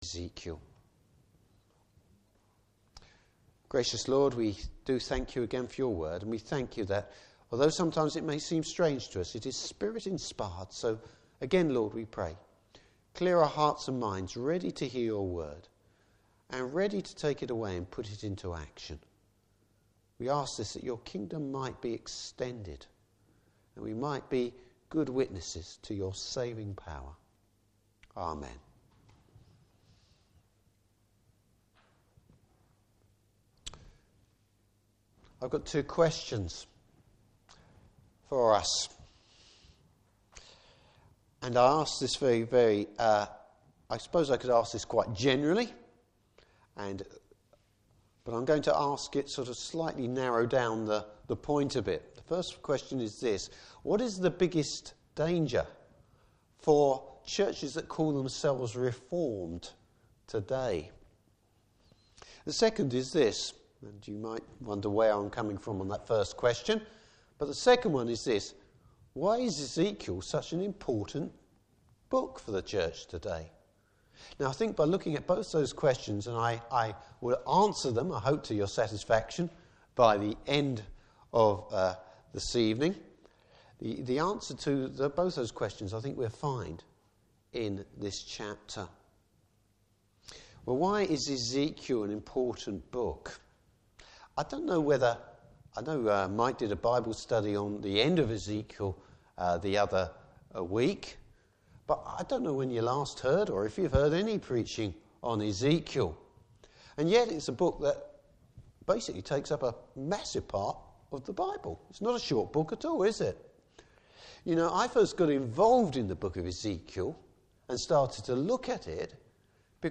Service Type: Evening Service Bible Text: Ezekiel 1.